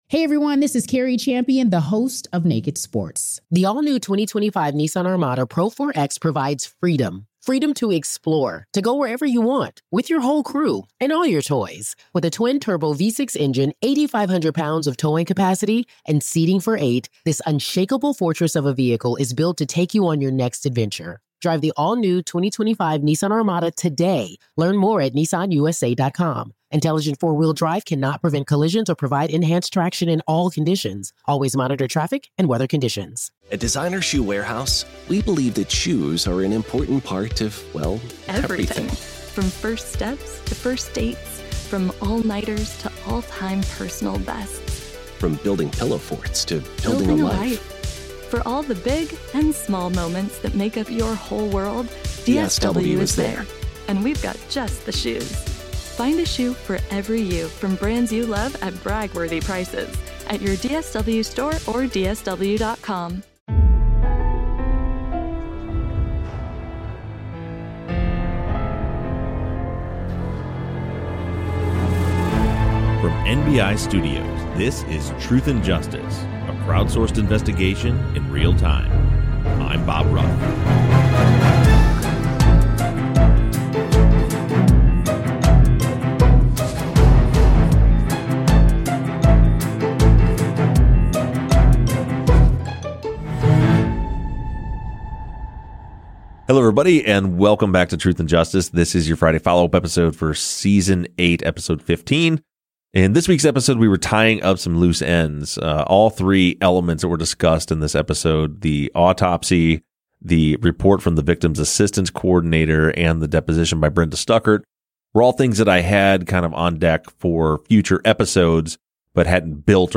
The guys answer listener questions gathered from social media as Season 8 winds down to a close.